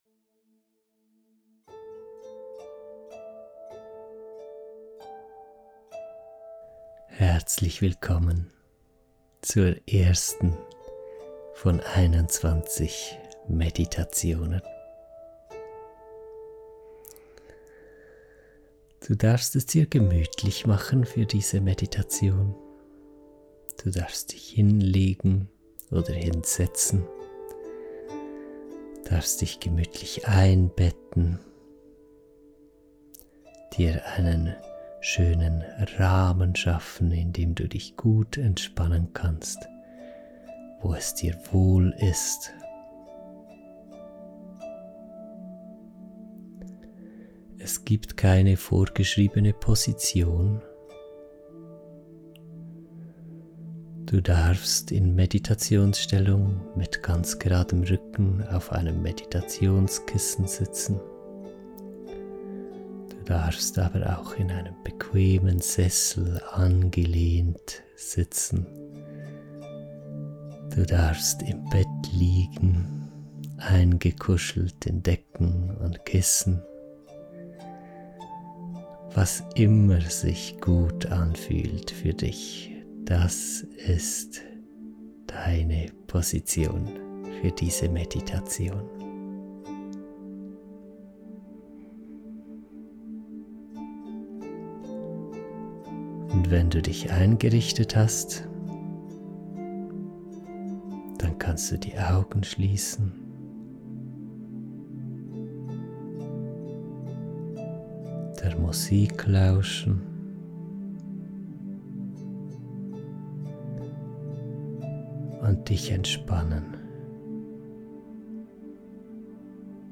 🧘🏼 Alles darf sein Meditation Tag 1 Alles darf sein Diese erste geführte Meditation begleitet dich dabei, ein Gefühl dafür zu entwickeln, was dir gut tut. Sie hilft dir zu verstehen, dass es kein richtig und falsch gibt.